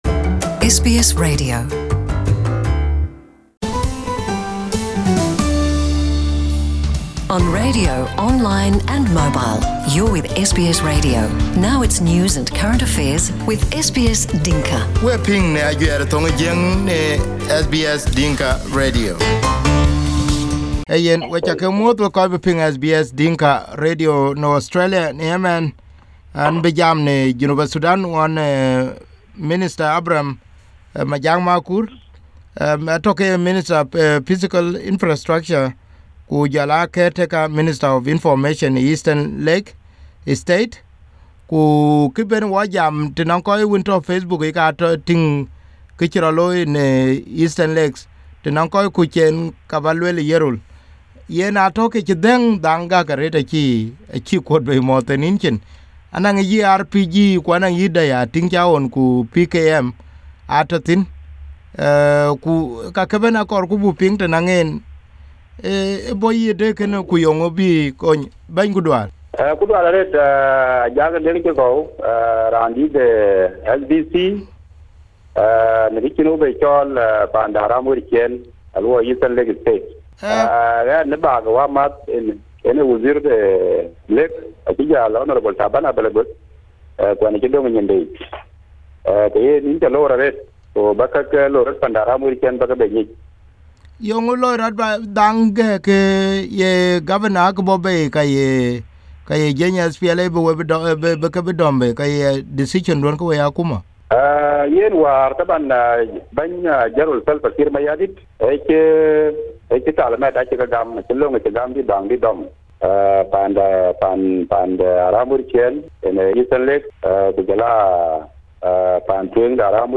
South Sudan Eastern Lake disarmed hundreds of civilians who owned the military types of guns. Following a directive from the President Kiir, the state Government coordinated with the zone commander of SPLA to disarmed civilians. To help explain this, we called Abraham Majak Makur, the current State Minister of Physical Infrastructure and acting Minister of Information, Eastern Lakes State.